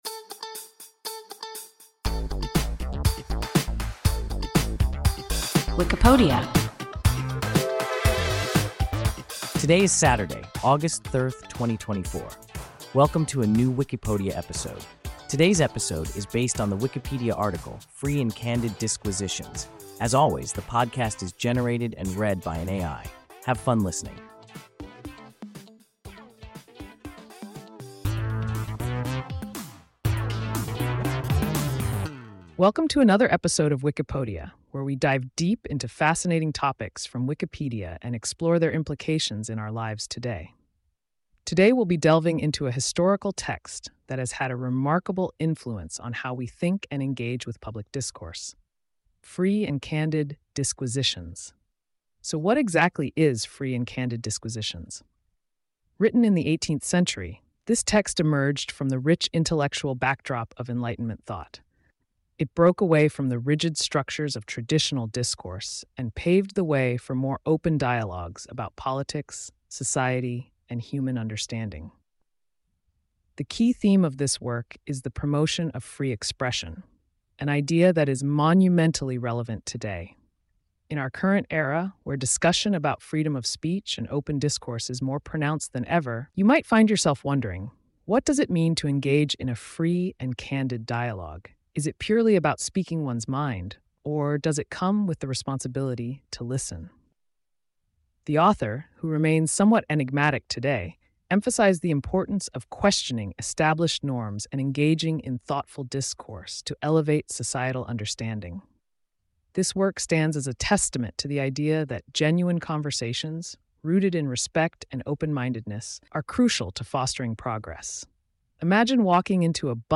Free and Candid Disquisitions – WIKIPODIA – ein KI Podcast